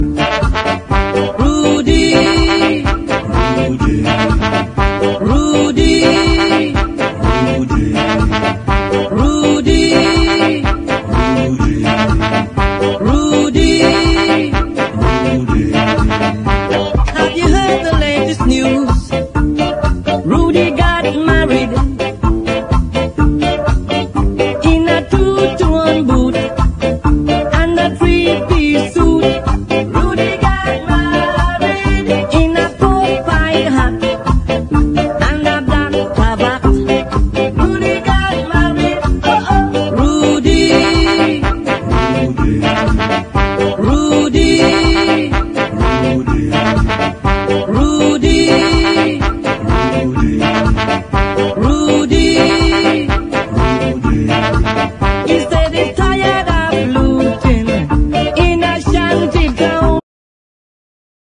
2-TONE SKA / NEO SKA
しかもこのドイツ盤にはダブ・ミックスを施したロング・ヴァージョンで収録！